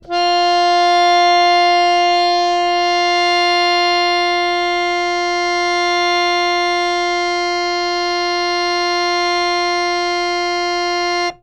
harmonium
F4.wav